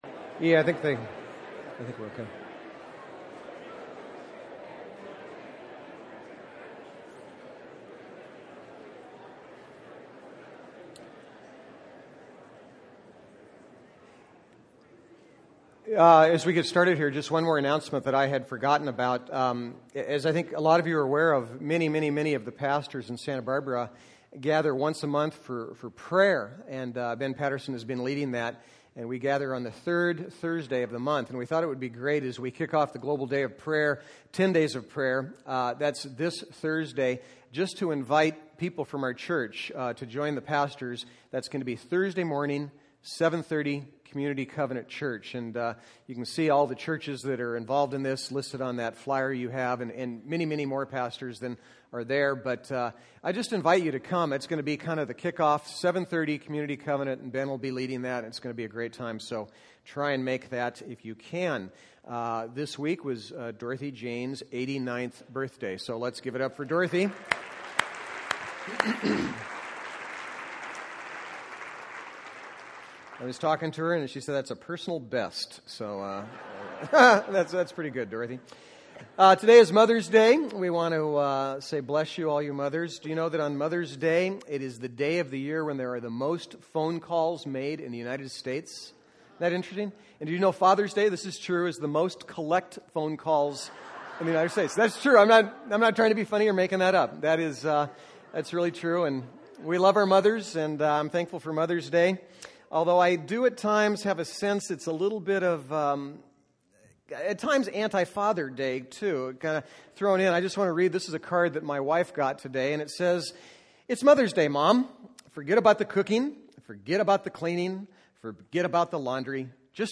Proverbs 31 Service Type: Sunday Bible Text